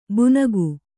♪ bunagu